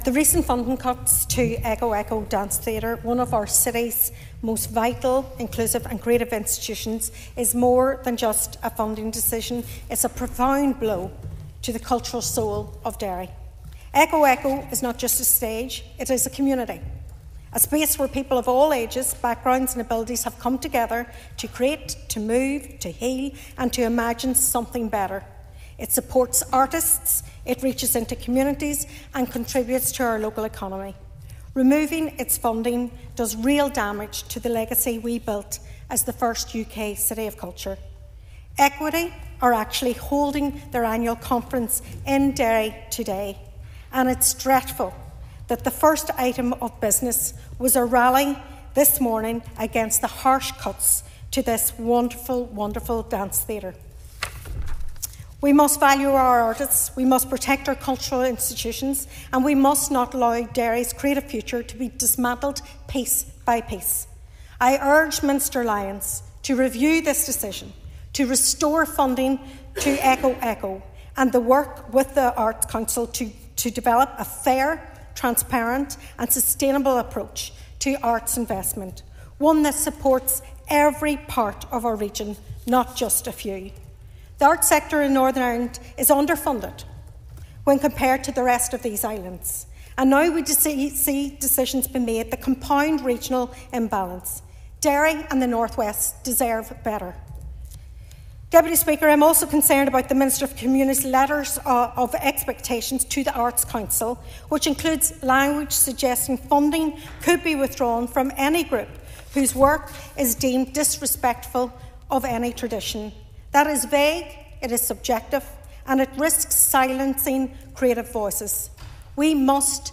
The Assembly has been told the future of the Echo Echo Theatre Company is in doubt following the withdrawal of funding.
Calling for funding to be restored, Foyle MLA Sinead McLaughlin urged Minister Gordon Lyons to review the decision and restore the funding, stressing the importance of culture and arts to Derry and the North West.